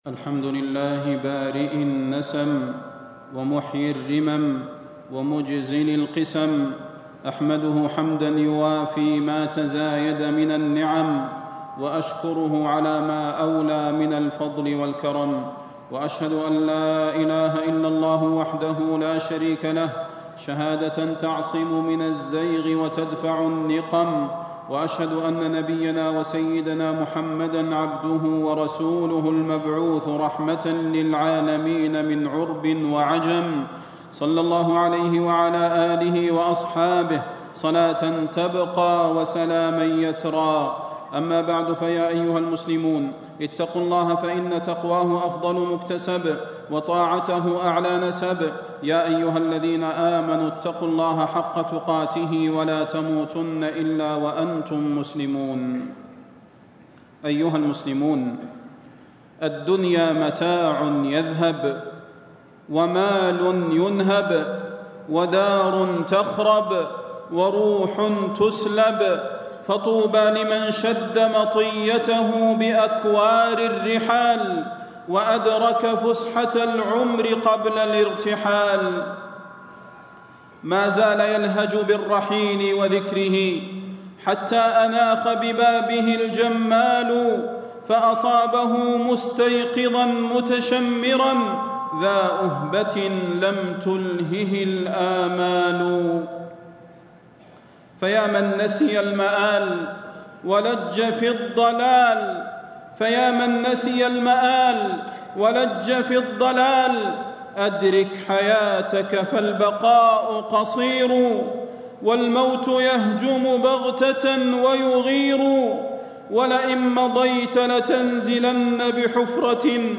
تاريخ النشر ٨ شوال ١٤٣٦ هـ المكان: المسجد النبوي الشيخ: فضيلة الشيخ د. صلاح بن محمد البدير فضيلة الشيخ د. صلاح بن محمد البدير المداومة على الطاعات